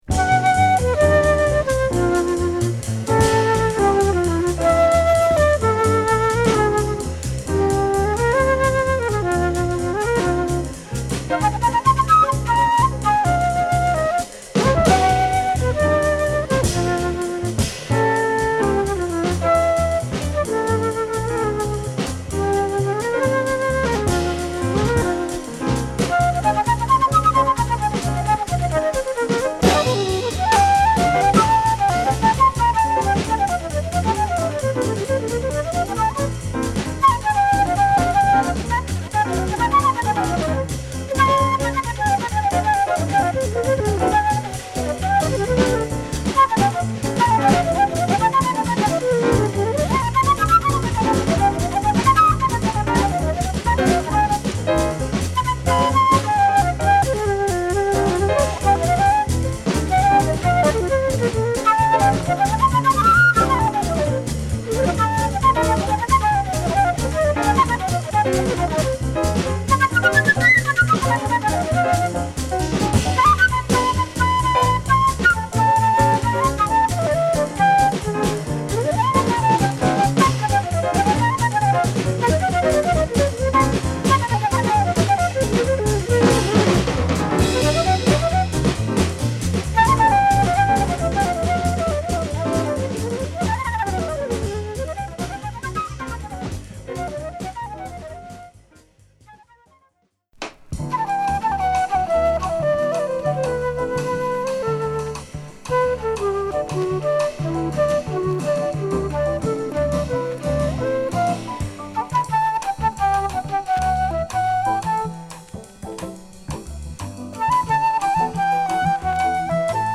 疾走感のあるA2
小気味良いボッサジャズに流麗なフルート〜ピアノが乗るB1